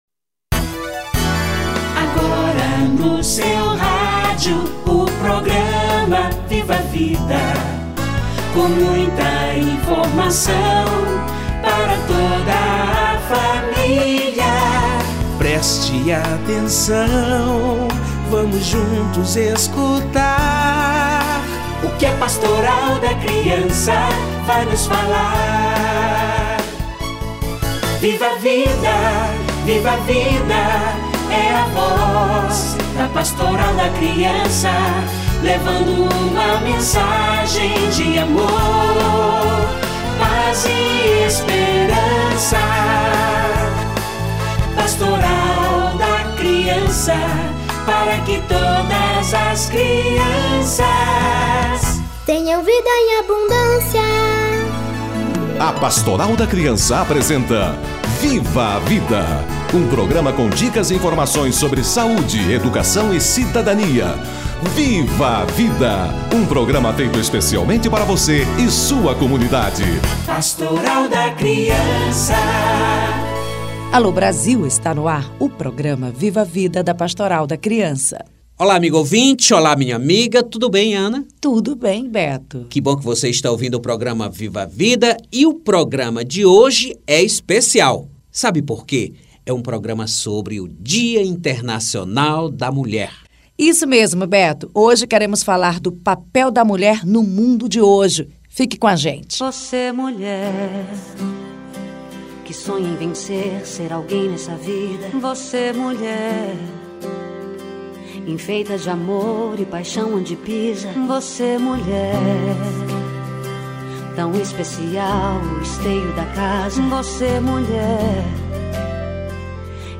Dia Internacional da Mulher - Entrevista